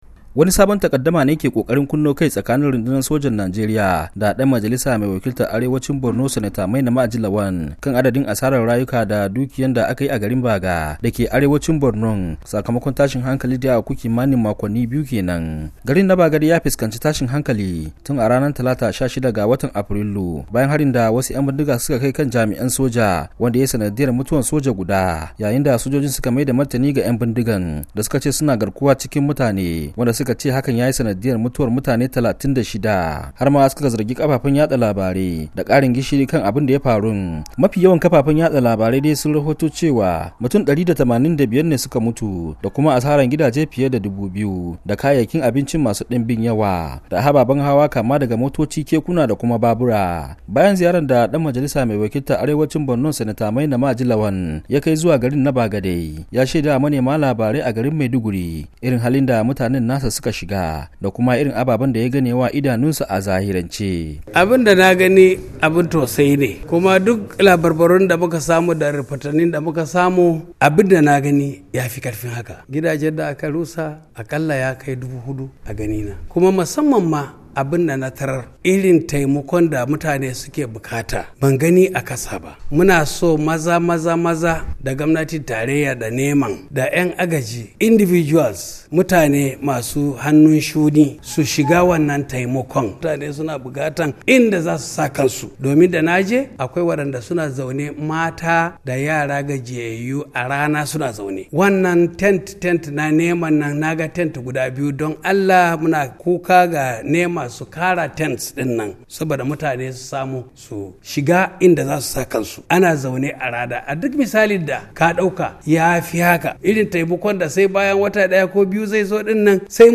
Ga rahoton wakilinmu